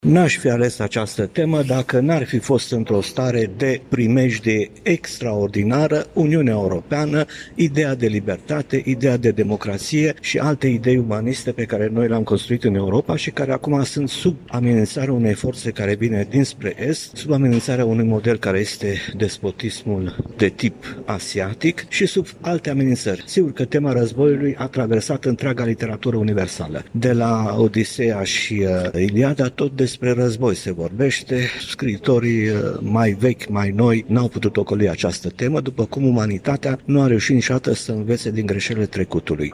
Afirmația a fost făcută astăzi de către scriitorul Matei Vișniec, la Universitatea ”Alexandru Ioan Cuza” din Iași, unde a conferențiat pe tema ”Literatură Dramatică și Război”.